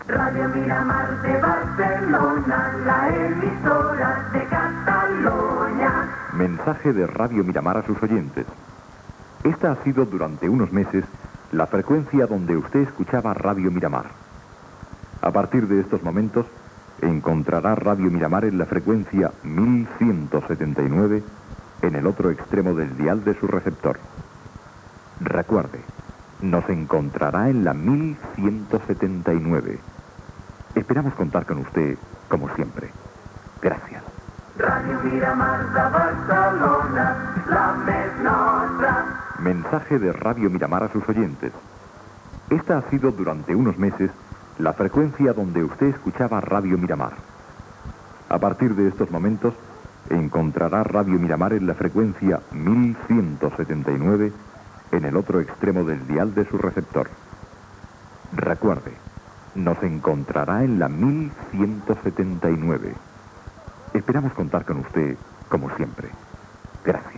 Indicatiu i avís del canvi de freqüència.